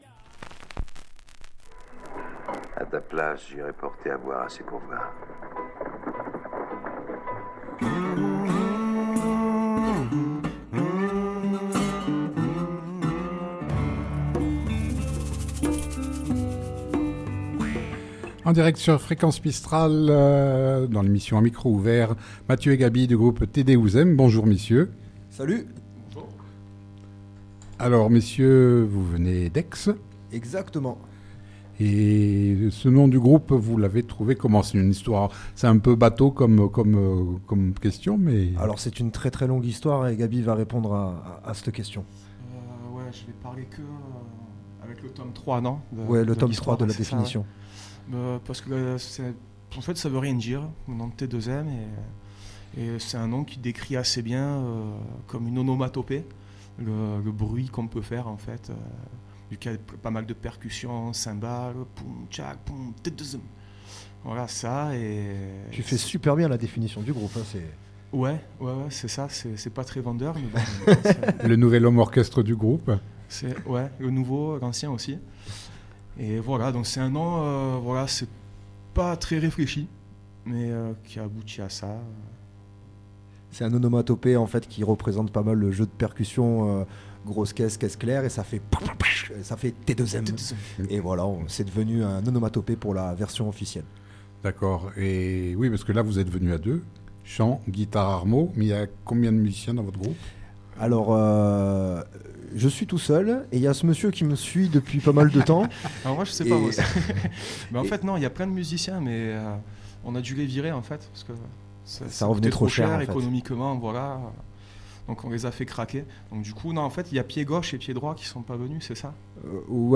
Entre rap (avec des paroles intelligibles) et hip-hop mâtinés de musiques issues de nombreuses origines, leur musique est entraînante avec des mots biens pensés.